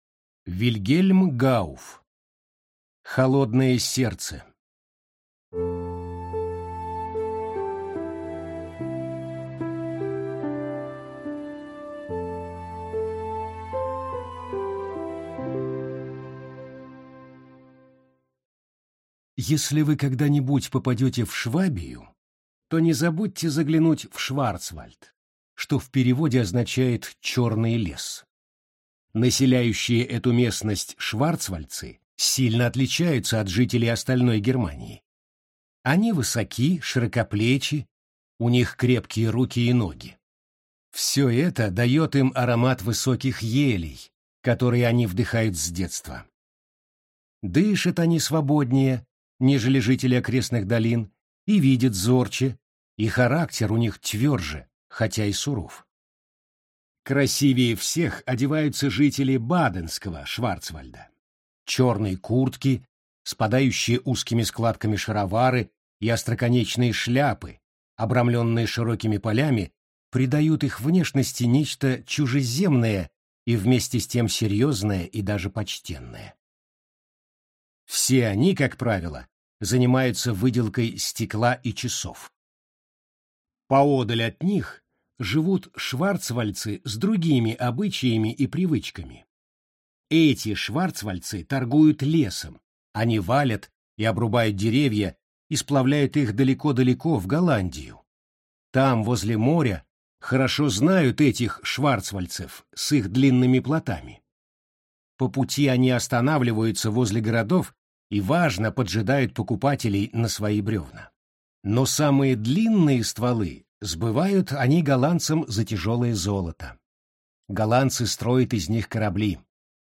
Аудиокнига Холодное сердце | Библиотека аудиокниг